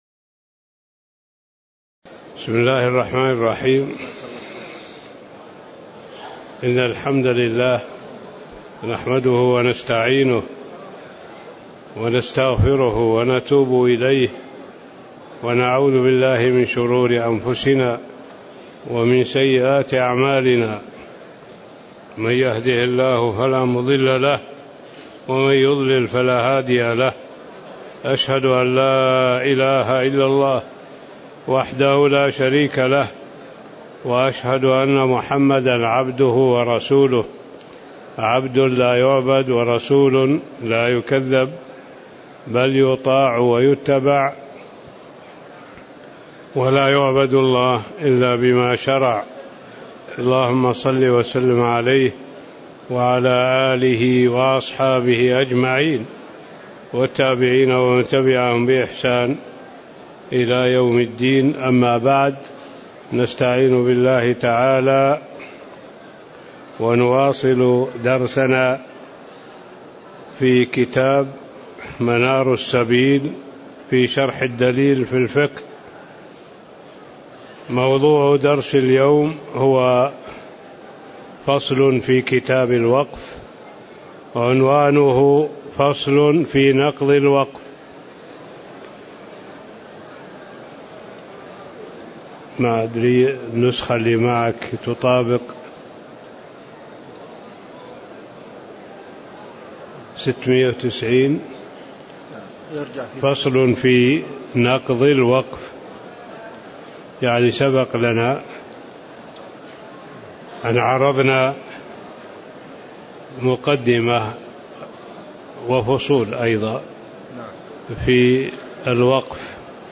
تاريخ النشر ١٣ ربيع الثاني ١٤٣٧ هـ المكان: المسجد النبوي الشيخ